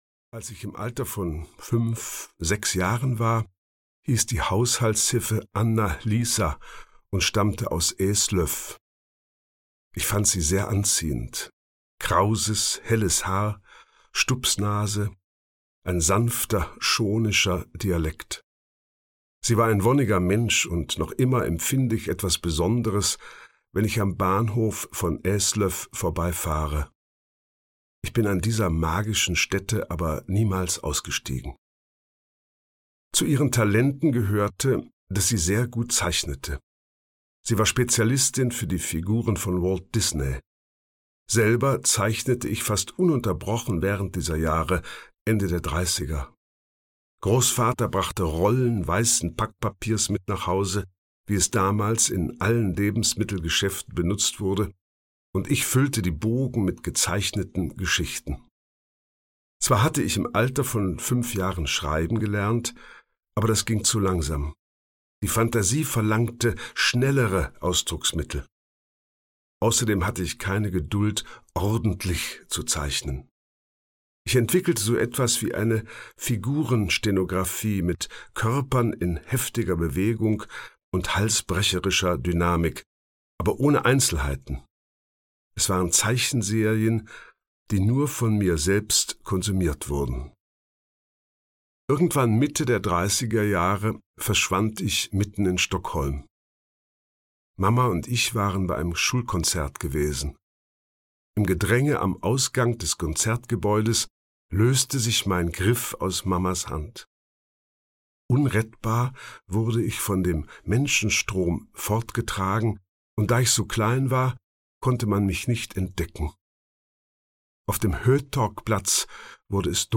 Hörbuch Die Erinnerungen sehen mich, Tomas Tranströmer.